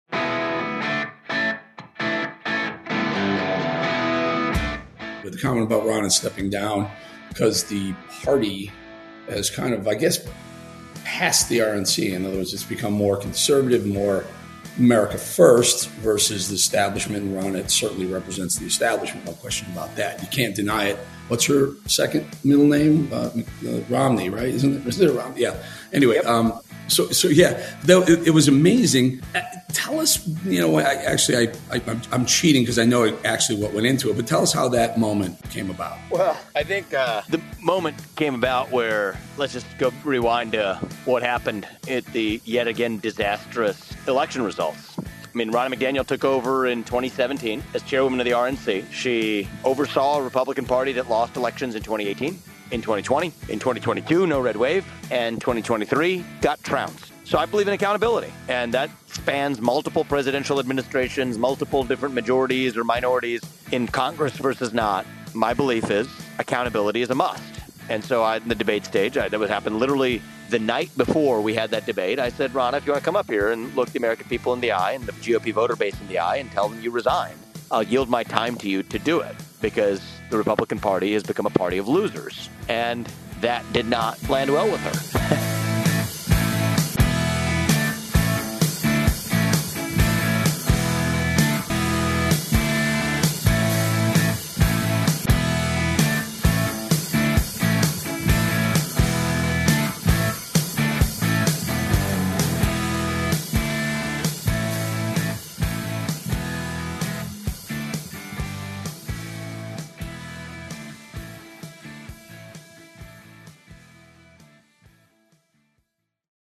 GOP presidential candidate Vivek Ramaswamy joins The Eric Bolling Podcast to discuss his now-viral moment from the third GOP debate in which he asked RNC Chair Ronna McDaniel to resign.